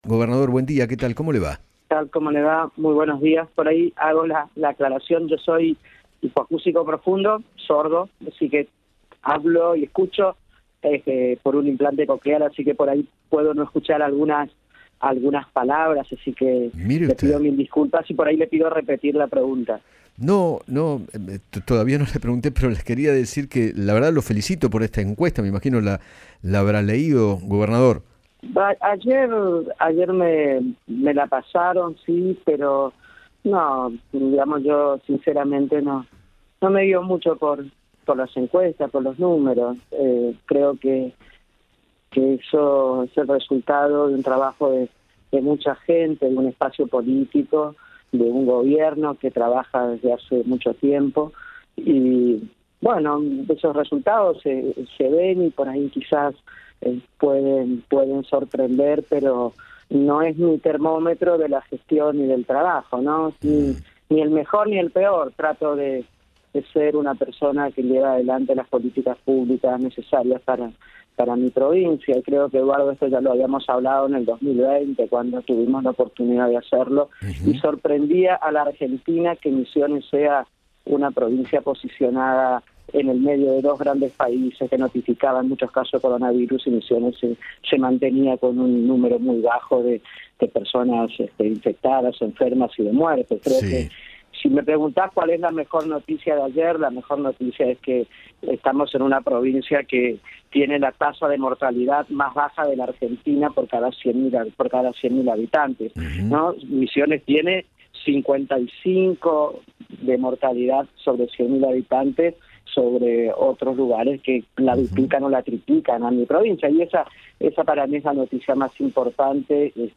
Oscar Herrera Ahuad, gobernador de Misiones, conversó con Eduardo Feinmann sobre la reciente medición publicada por la Consultora CB, la cuál, lo ubica como el gobernador con “mejor imagen”. Además, reveló su discapacidad auditiva y contó detalles de su día a día conviviendo con la hipoacusia.